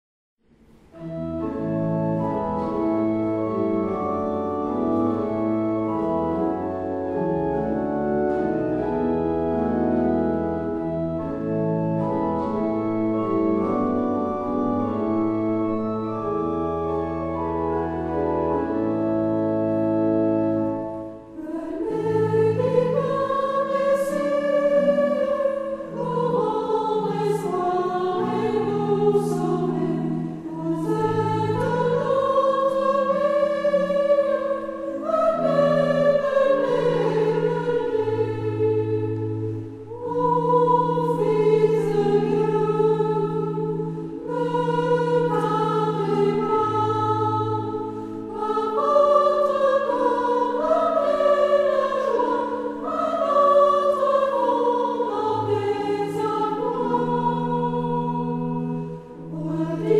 Género/Estilo/Forma: Cántico ; Sagrado
Carácter de la pieza : energico
Tipo de formación coral: SATB  (4 voces Coro mixto )
Tonalidad : la mayor